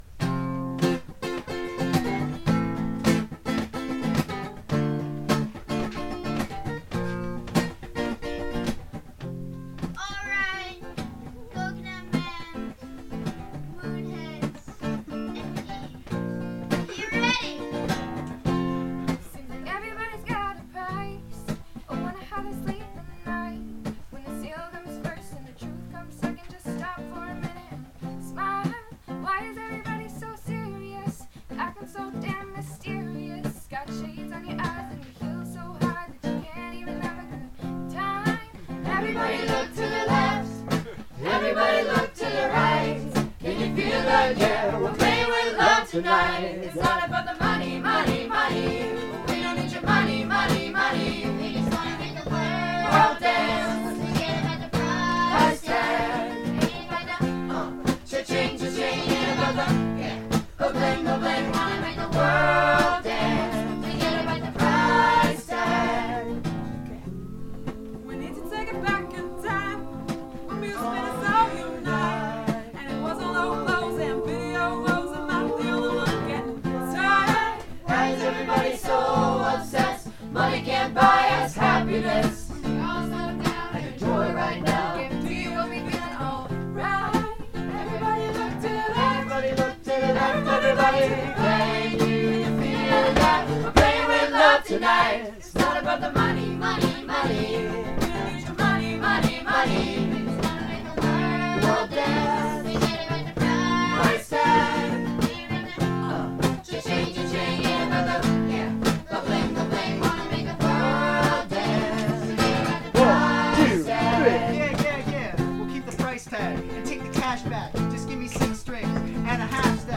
Madrigals